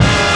JAZZ STAB 18.wav